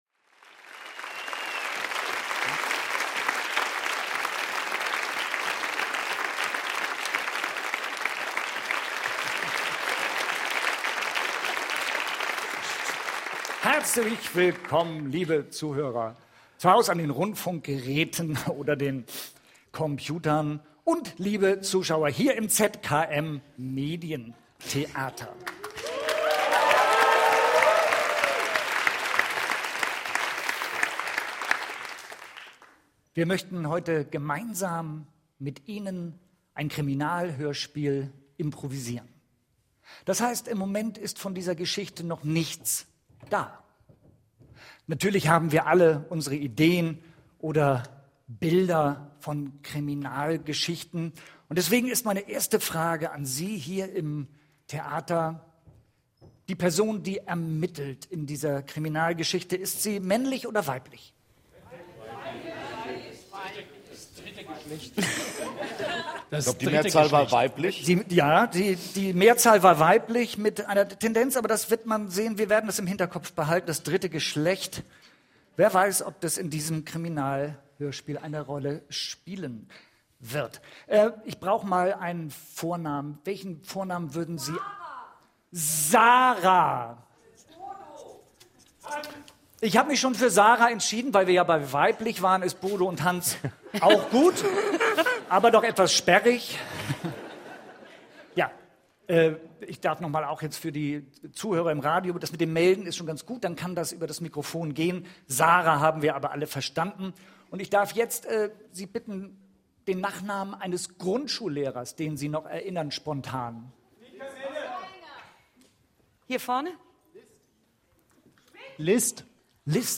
Hörspiele in voller Länge kostenlos live hören: Eine Leiche im Hafen - Aufzeichnung eines Impro-Live-Hörspiels nach Vorschlägen der